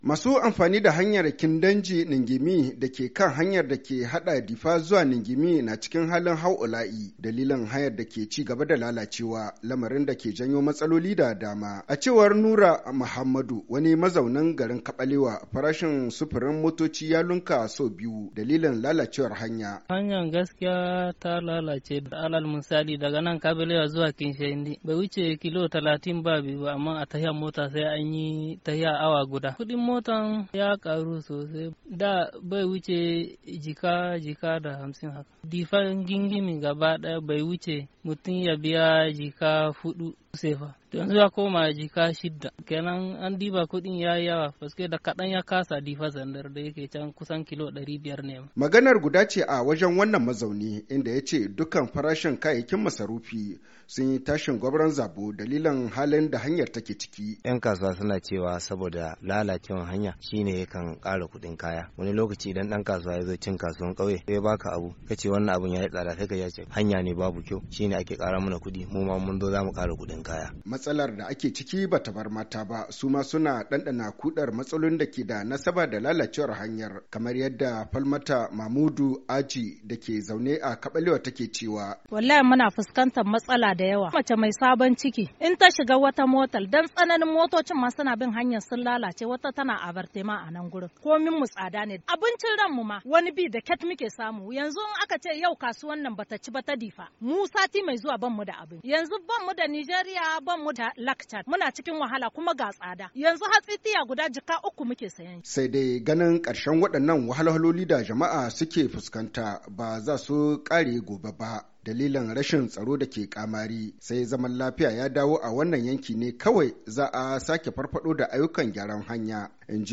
Voici son reportage.